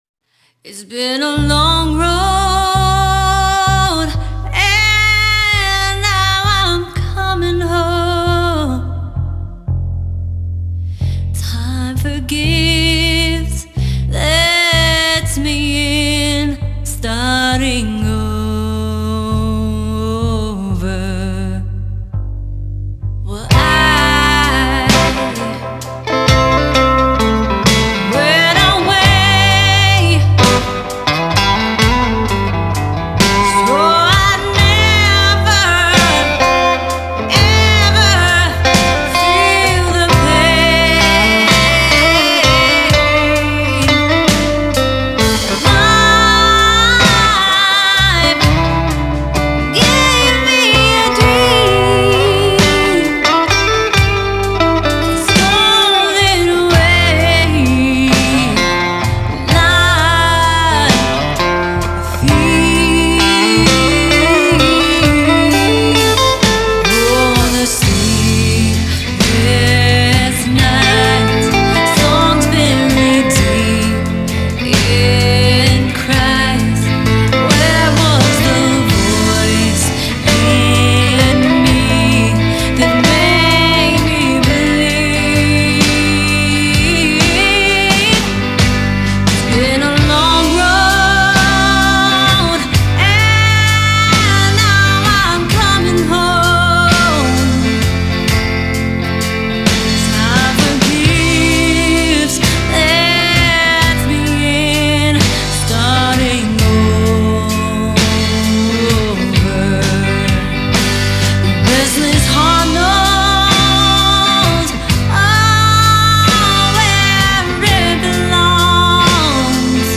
Killer Vocals, Killer Guitar, Killer Rock !!
Genre: Blues, Blues Rock